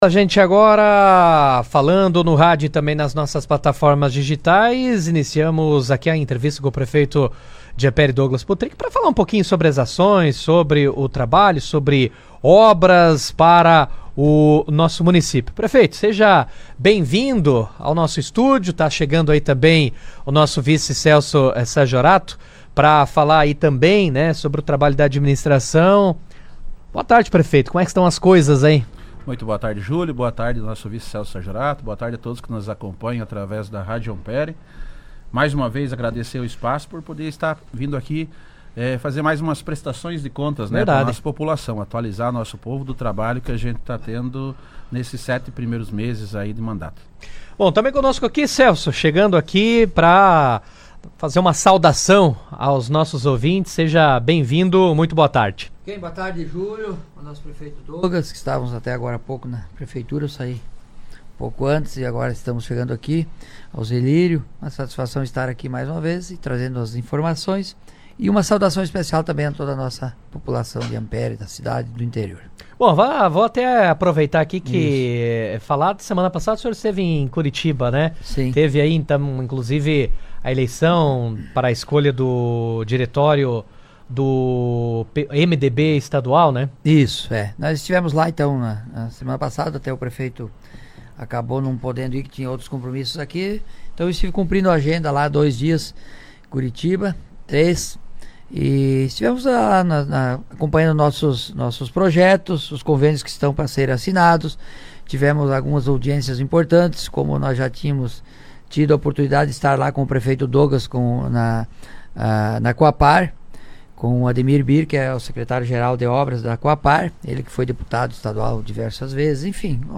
Na tarde desta terça-feira, 29, o prefeito de Ampére, Douglas Potrich, e o vice-prefeito, Celso Saggiorato, participaram ao vivo do Jornal RA 2ª Edição, da Rádio Ampére. Durante a entrevista, a dupla avaliou os primeiros sete meses da atual gestão municipal, destacando as principais ações desenvolvidas nesse período, especialmente nas áreas de infraestrutura, saúde e educação.